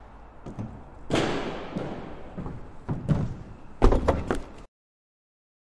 废弃的工厂 金属后世界末日的回声" 空心表面的脚步声
描述：记录在爱尔兰都柏林的废弃工厂。使用Zoom H6和Rode NT4。
Tag: 空间 噪音 金属 工业 回声